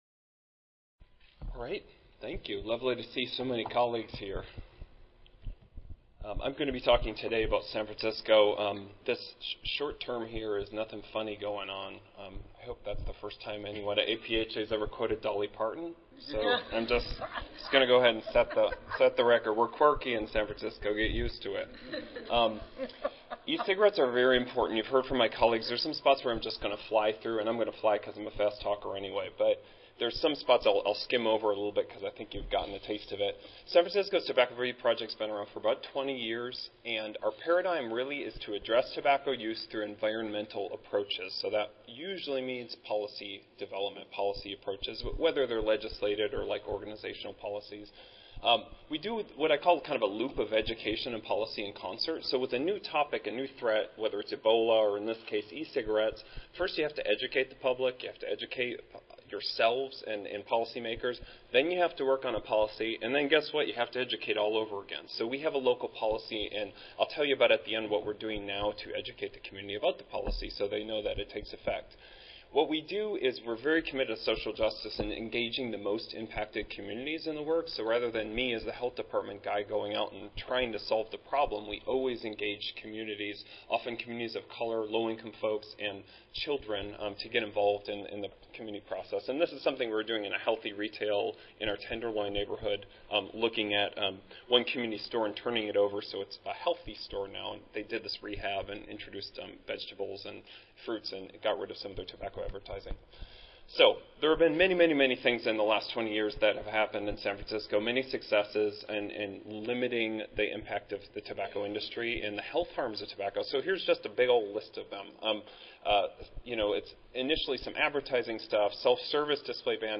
142nd APHA Annual Meeting and Exposition (November 15 - November 19, 2014): Clearing the air on the harms of e-cigarette sales and use
Recorded Presentation